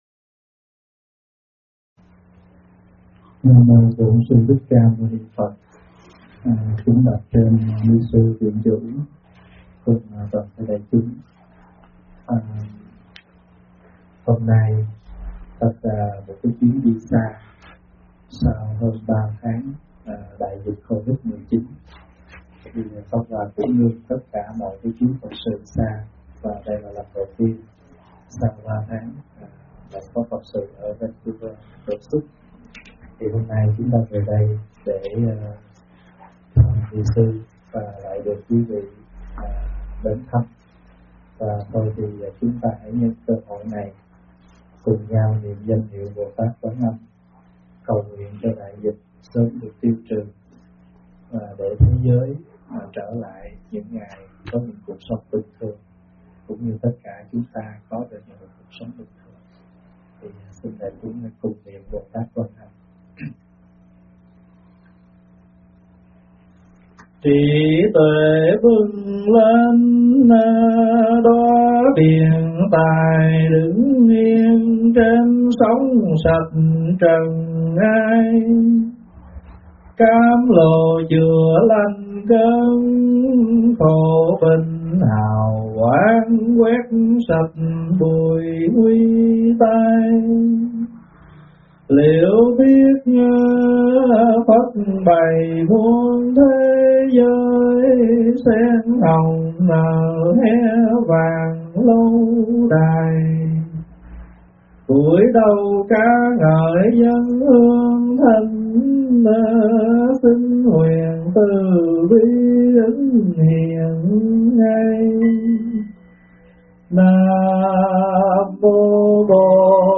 Thuyết pháp Vui Sống Bình Thường - ĐĐ.
Vui Sống Bình Thường - ĐĐ. Thích Pháp Hòa giảng tại đạo tràng Pháp Hoa, Vancouver, BC, Canada ngày 10 tháng 7 năm 2020